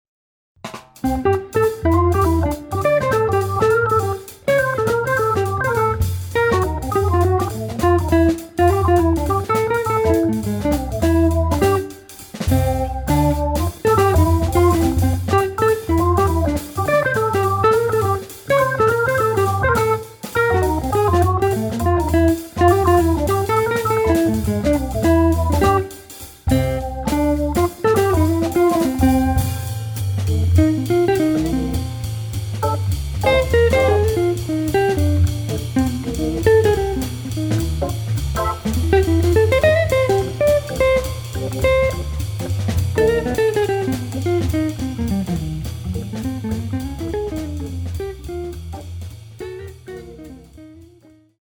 guitar
Hammond organ
drums